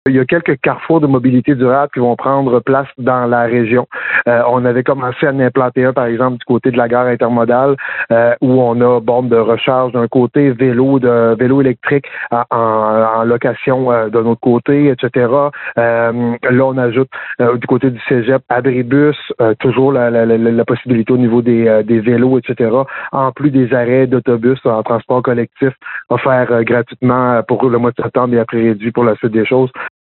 De plus, cet automne, le campus de Gaspé va accueillir un carrefour de mobilité durable, c’est-à-dire un abribus, un support à vélos ainsi que deux vélos électriques, en collaboration avec la RÉGIM et la ville de Gaspé. Le maire et président de la RÉGIM, Daniel Côté, précise qu’on en verra de plus en plus à Gaspé :